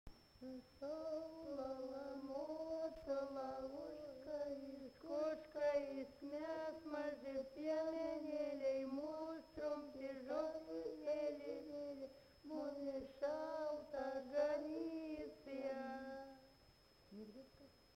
Subject daina
Erdvinė aprėptis Liškiava
Atlikimo pubūdis vokalinis